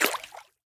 Minecraft Version Minecraft Version snapshot Latest Release | Latest Snapshot snapshot / assets / minecraft / sounds / mob / axolotl / attack4.ogg Compare With Compare With Latest Release | Latest Snapshot